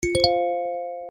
• Качество: 129, Stereo
Стандартное смс